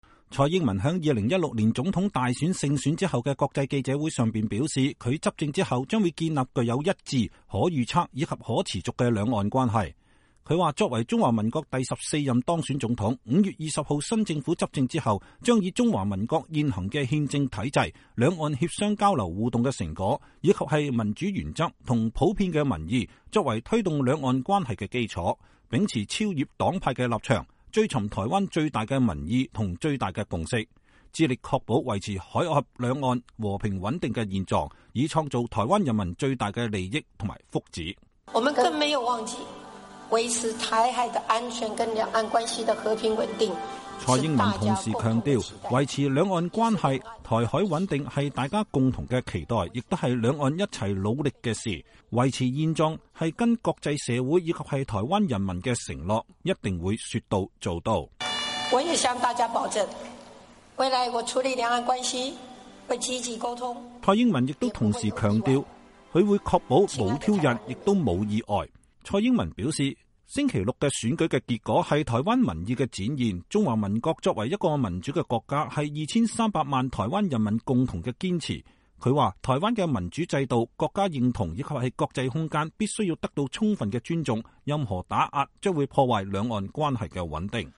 蔡英文在2016年總統大選勝選後的國際記者會上表示，她執政後將建立具有一致、可預測、以及可持續的兩岸關係。